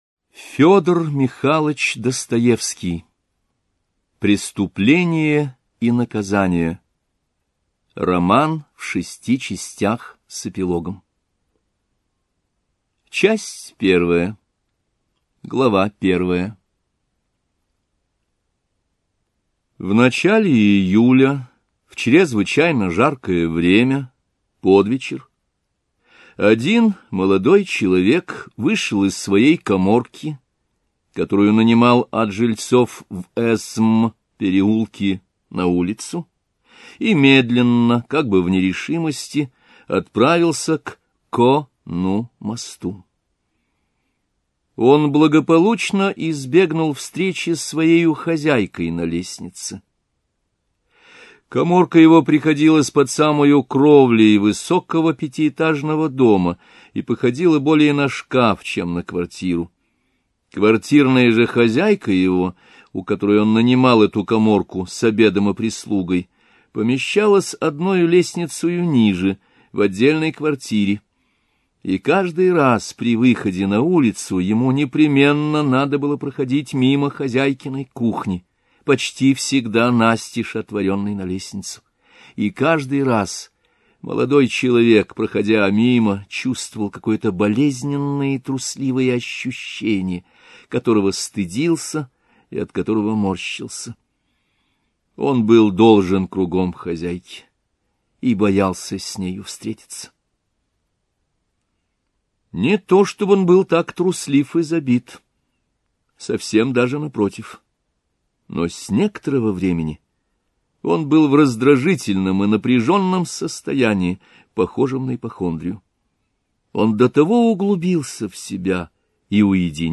Аудиокнига Преступление и наказание | Библиотека аудиокниг
Прослушать и бесплатно скачать фрагмент аудиокниги